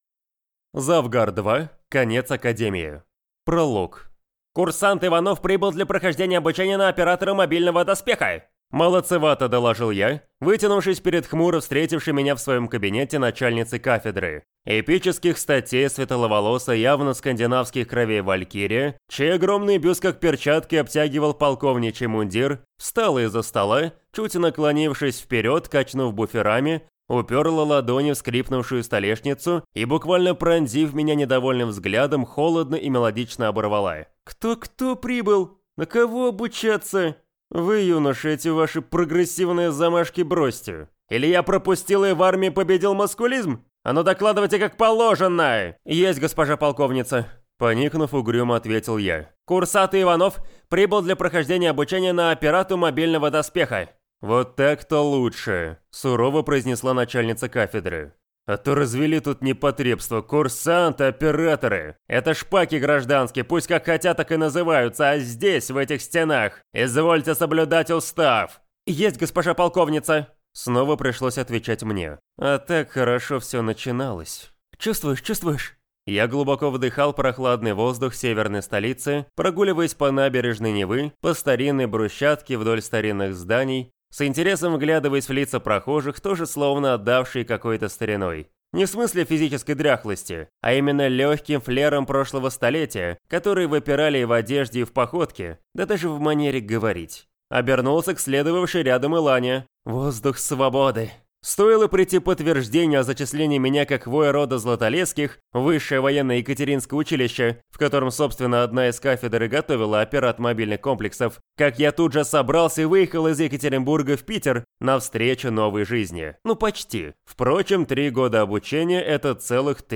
Аудиокнига Конец академии | Библиотека аудиокниг